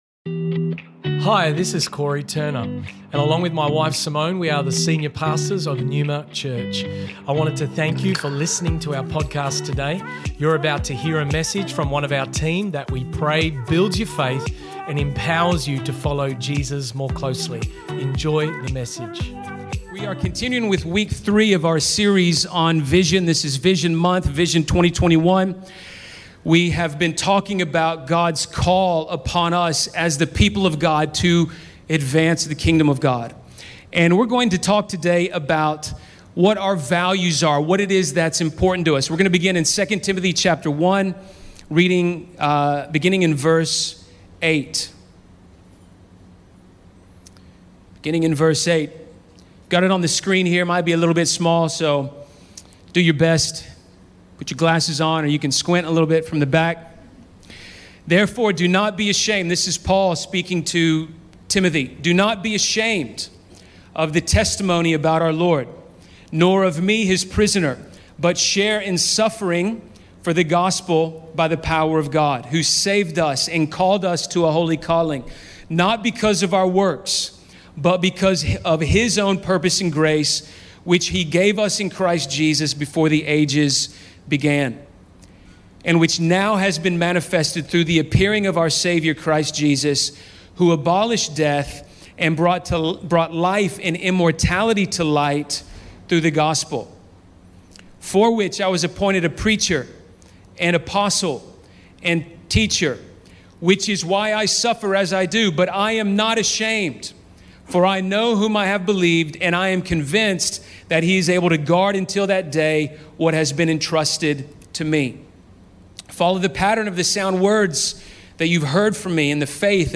Kingdom Culture Vision Month 2021 - Week 3 Originally recorded at Neuma Church Perth, Australia on March 7th 2021.